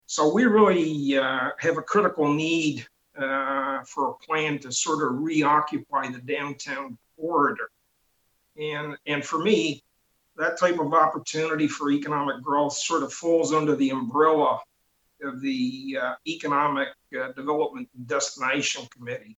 Belleville city council virtual meeting, June 8, 2020 (Belleville YouTube)